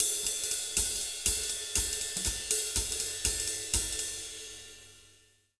240SWING01-L.wav